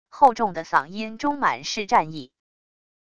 厚重的嗓音中满是战意wav音频